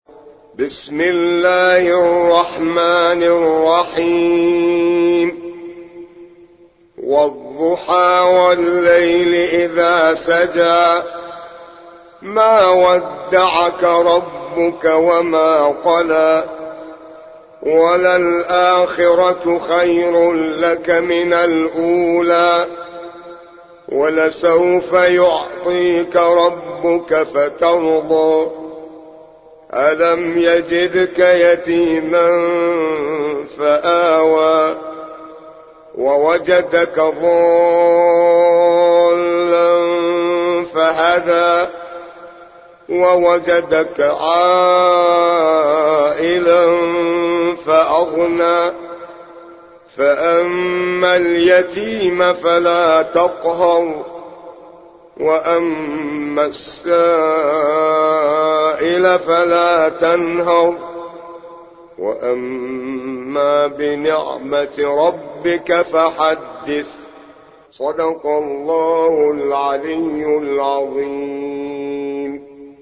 93. سورة الضحى / القارئ